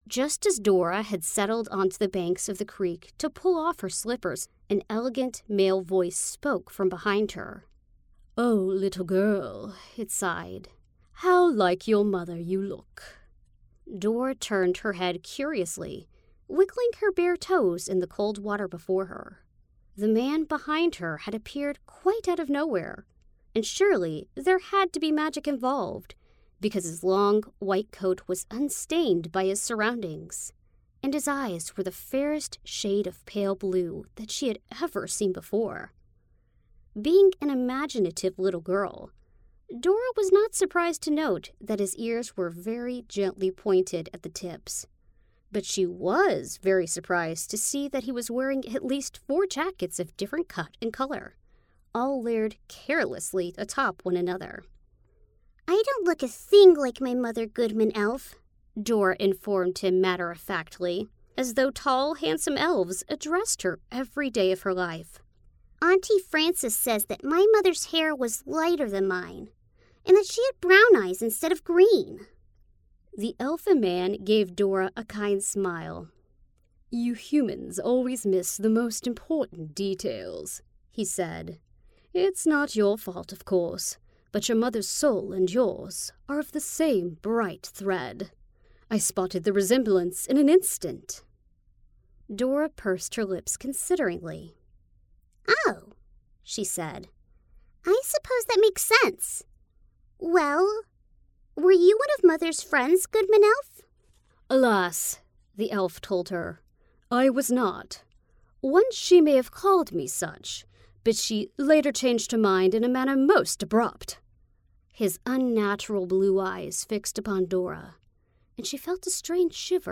Audiobook
My voice has been described as sassy and quirky, but I can still be warm and comforting.